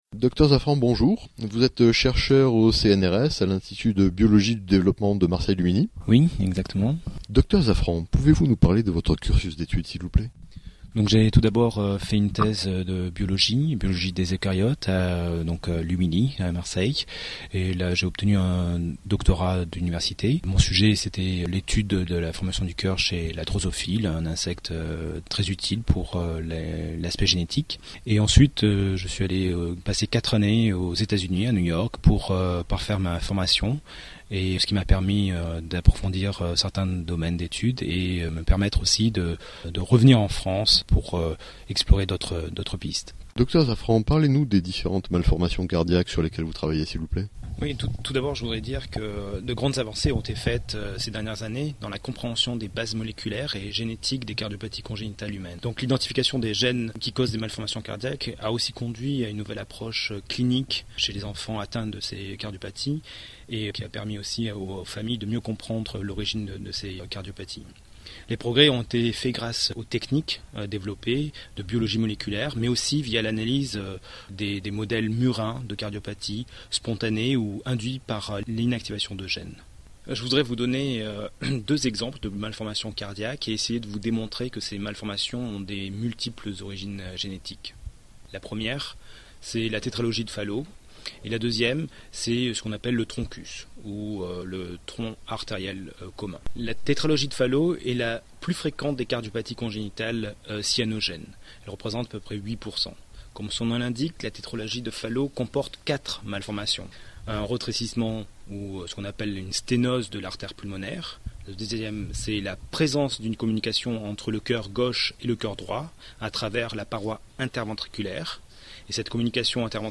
Dans cette section, vous trouverez les interviews podcastées de chercheurs dans tous les secteurs de la santé et du vivant en général.